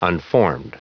Prononciation du mot unformed en anglais (fichier audio)
Prononciation du mot : unformed